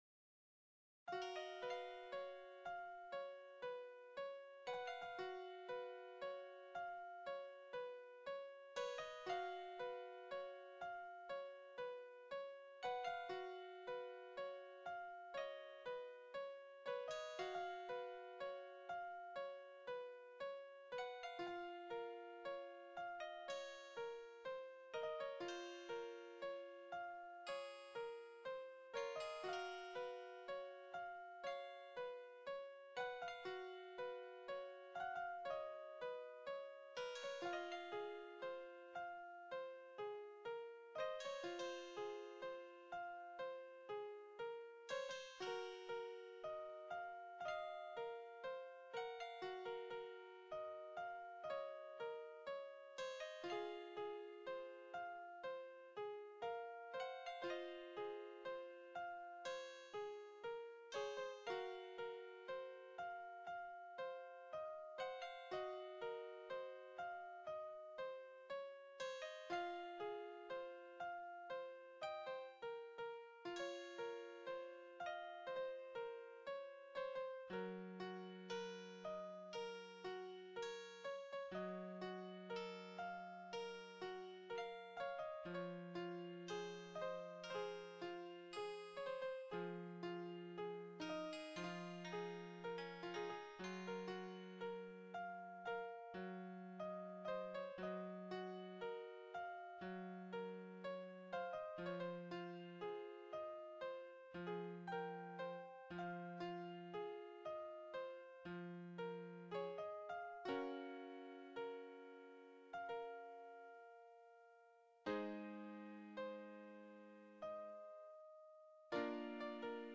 Another Tune I made, Oriental Style